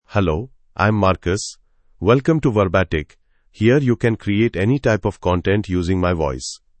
Marcus — Male English (India) AI Voice | TTS, Voice Cloning & Video | Verbatik AI
MaleEnglish (India)
Marcus is a male AI voice for English (India).
Voice sample
Marcus delivers clear pronunciation with authentic India English intonation, making your content sound professionally produced.